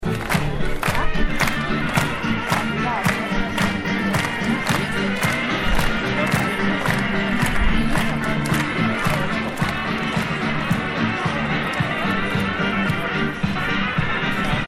دانلود آهنگ دست زدن با موسیقی در پس زمینه از افکت صوتی انسان و موجودات زنده
دانلود صدای دست زدن با موسیقی در پس زمینه از ساعد نیوز با لینک مستقیم و کیفیت بالا
جلوه های صوتی